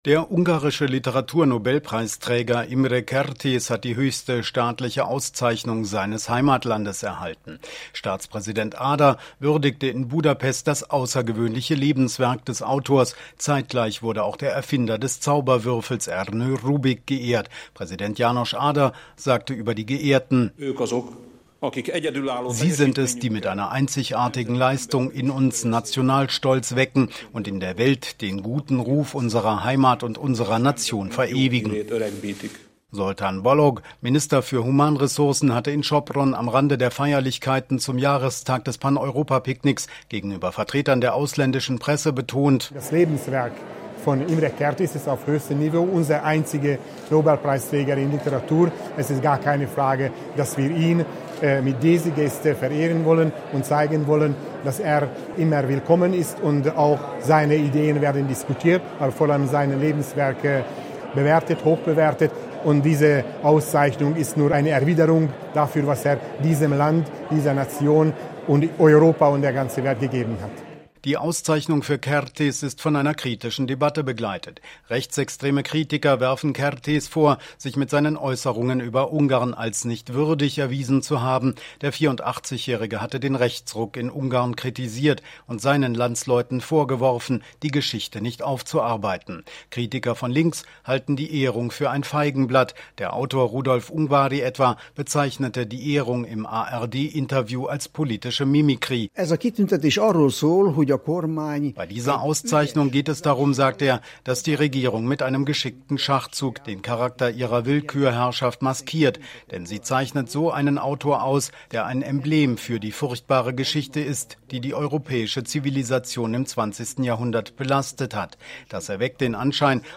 Kertesz`Auszeichnung von Debatte begleitet – Ein Audiobeitrag